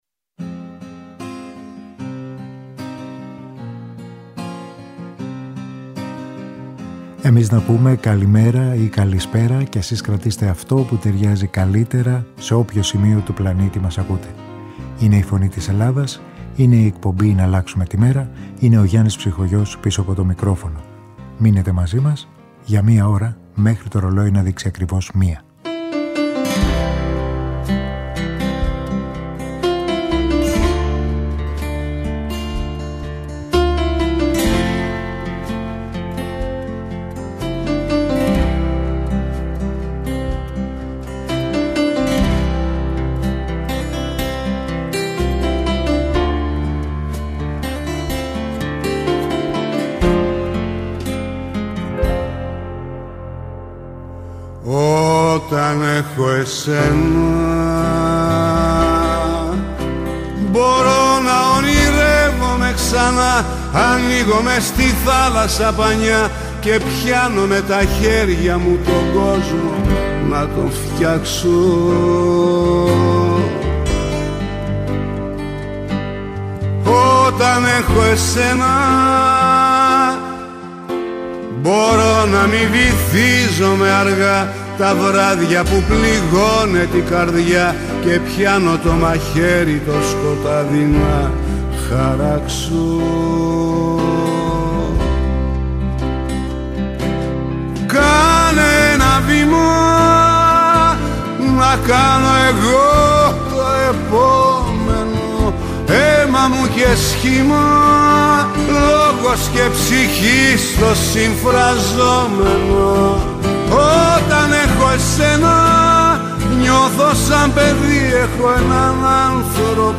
Στην αρχή μιας νέας μέρας με μουσικές
Μουσική